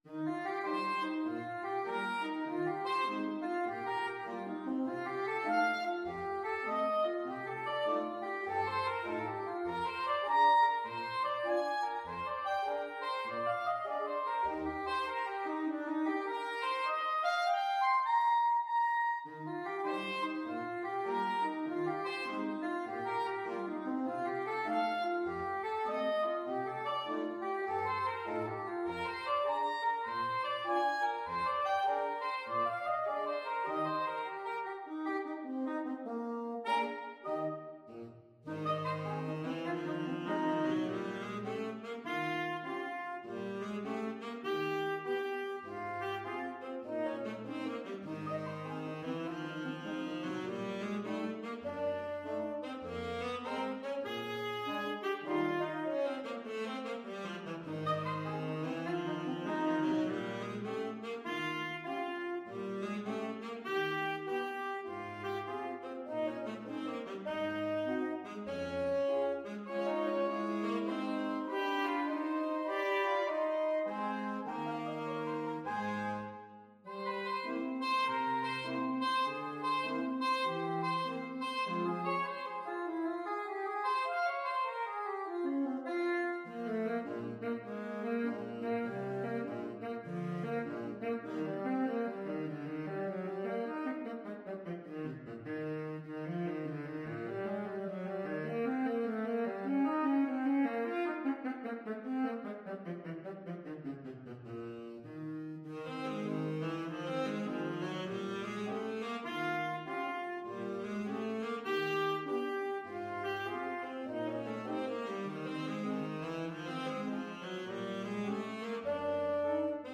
4/4 (View more 4/4 Music)
Lightly = c. 100
Jazz (View more Jazz Saxophone Quartet Music)